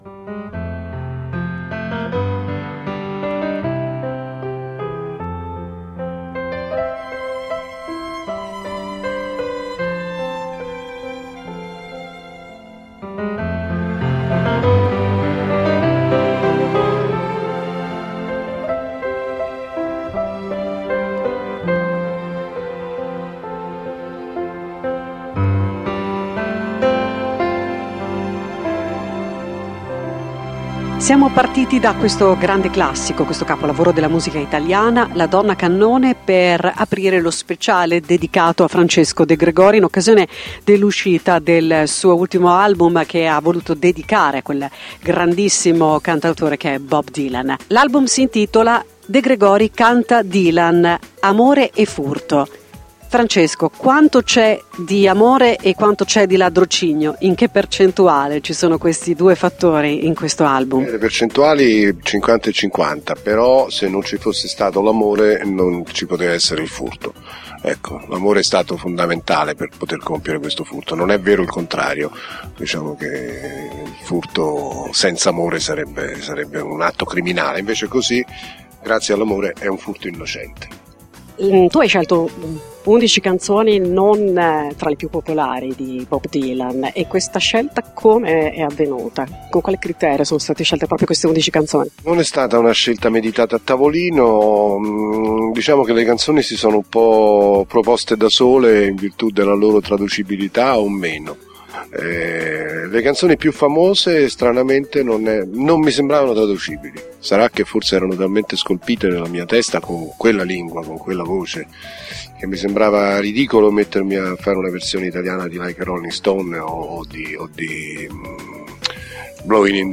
L’INTERVISTA A FRANCESCO DE GREGORI
Intervista-De-Gregori.mp3